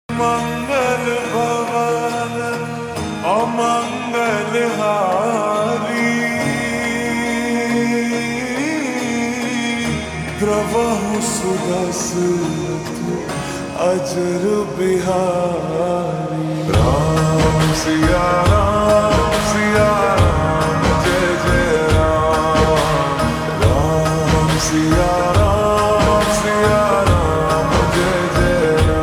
(Slowed + Reverb)